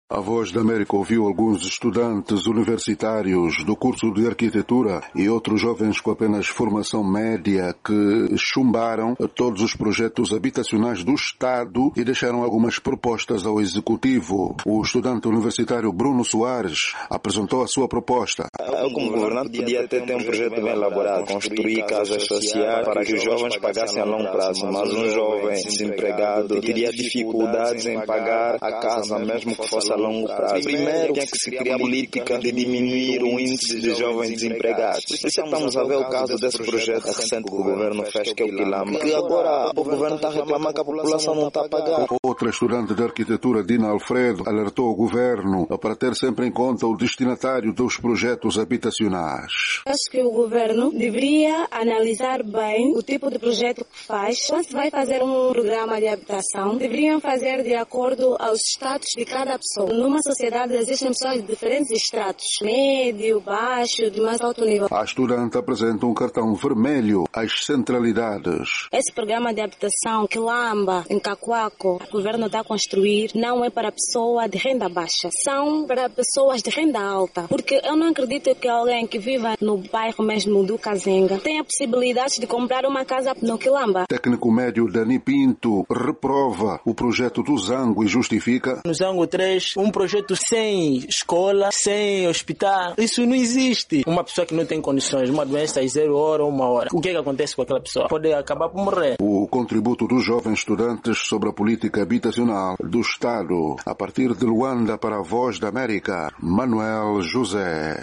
A VOA ouviu alguns estudantes universitários do curso de arquitectura e outros jovens com formação média que chumbaram todos os projectos habitacionais do Estado e deixaram algumas propostas ao Executivo.